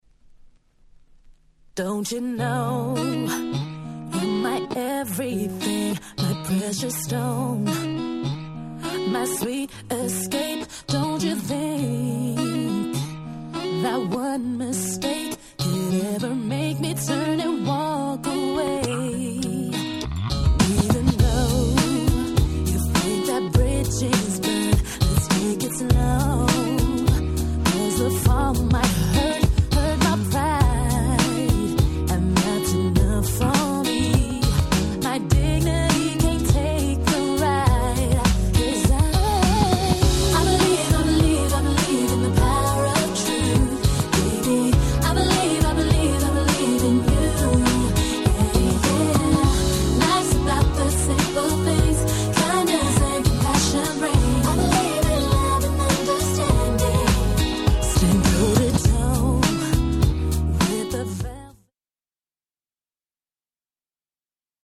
White Press Only Nice R&B Complilation !!!!!